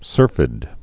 (sûrfĭd)